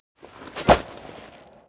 rogue_attk_2.wav